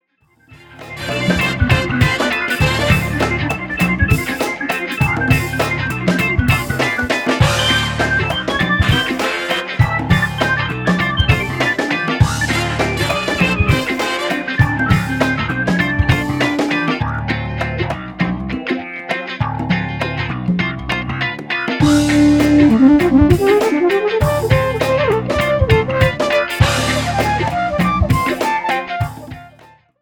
FUNK  (2.27)